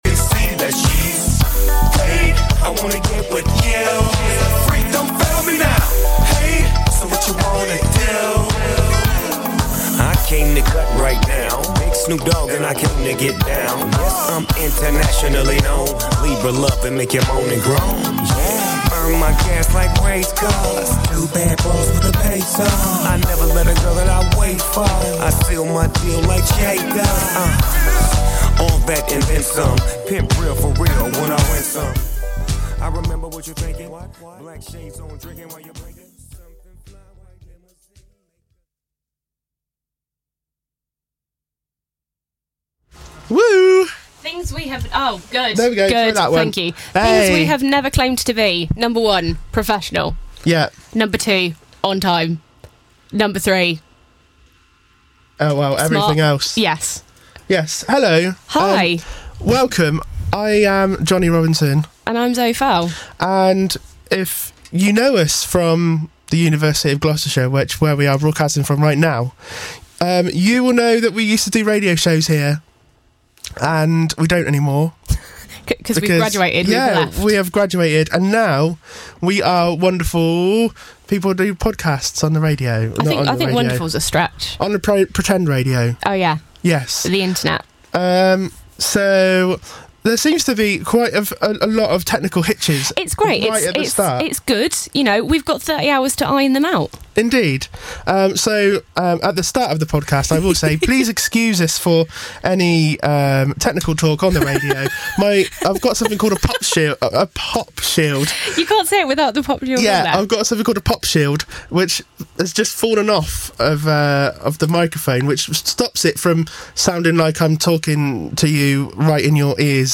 kick off their 30-hour podcast live from Tone Radio in Cheltenham!